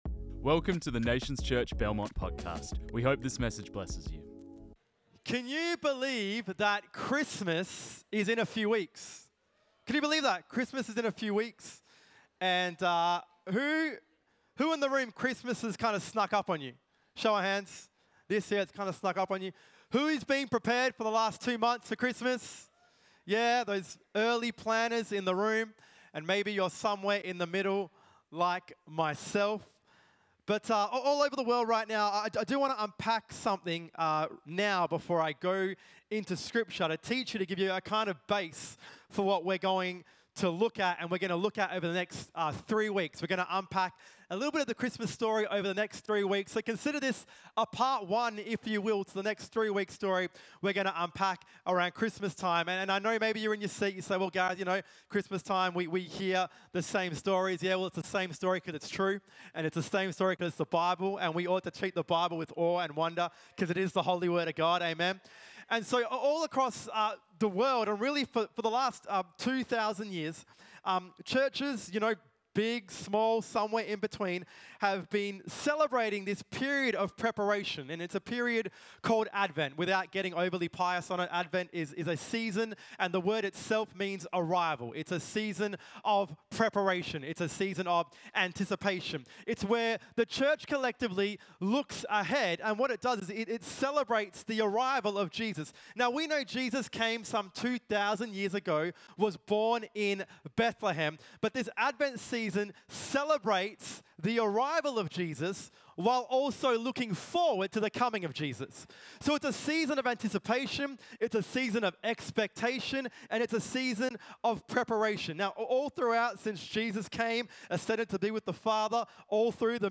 This message was preached on 08 December 2024